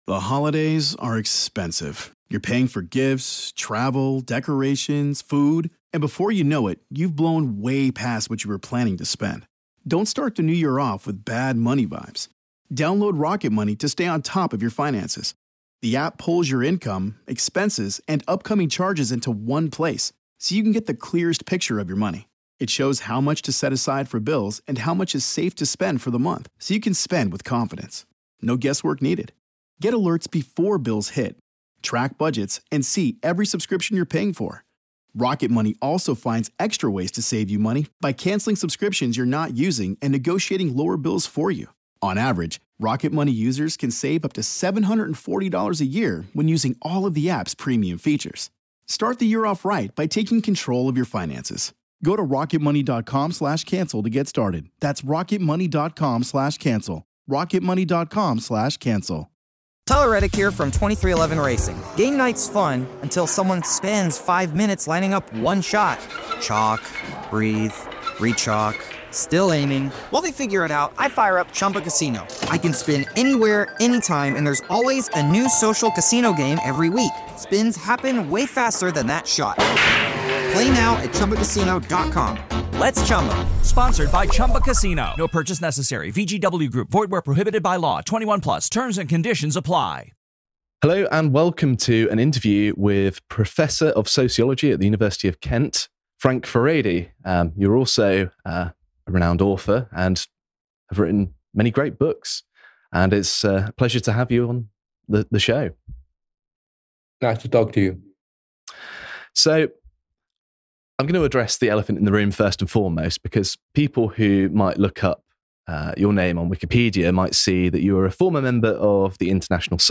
Interview with Professor Frank Furedi